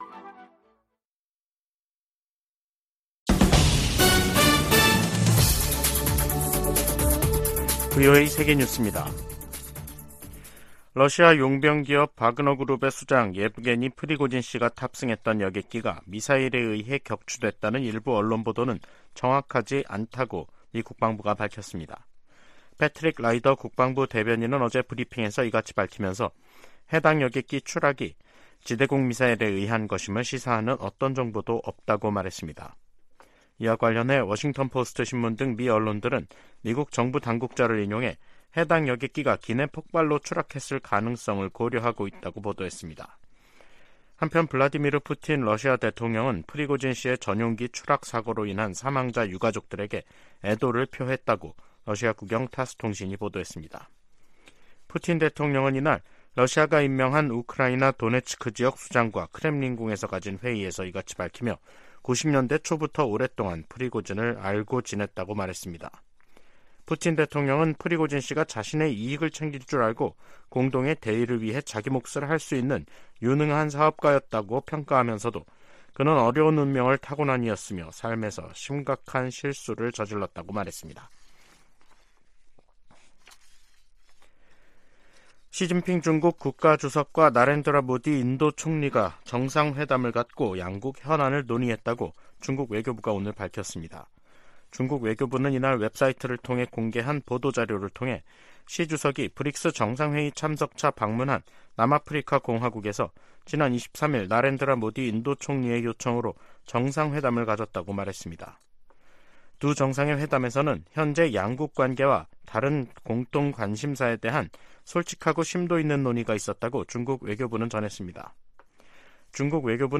VOA 한국어 간판 뉴스 프로그램 '뉴스 투데이', 2023년 8월 25일 2부 방송입니다. 유엔 안전보장이사회가 25일 미국 등의 요청으로 북한의 위성 발사에 대한 대응 방안을 논의하는 공개 회의를 개최합니다. 미 국방부가 북한의 2차 정찰위성 발사를 비판하며 지역의 불안정을 초래한다고 지적했습니다. 북한 해킹조직이 탈취한 거액의 암호화폐를 자금세탁해 현금화할 가능성이 있다고 미국 연방수사국(FBI)이 경고했습니다.